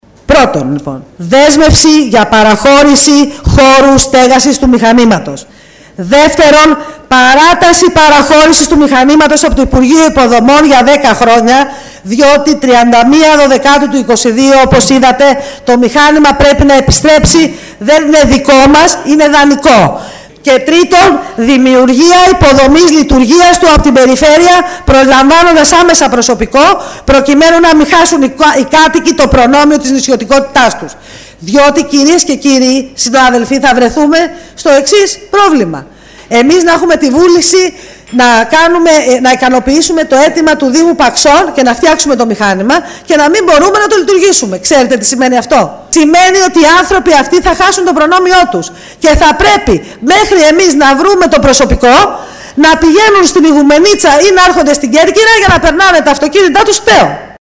Στη συνέχεια παραθέτουμε αποσπάσματα από την ομιλία της αντιπεριφερειάρχη Μελίτας Ανδριώτη και του Αντιδημάρχου Παξών Μανώλη Βλαχόπουλου: